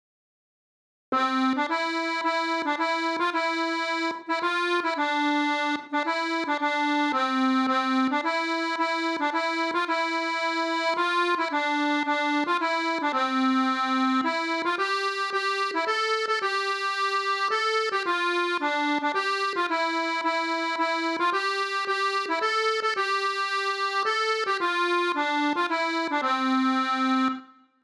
Chants de marins